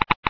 geiger3.ogg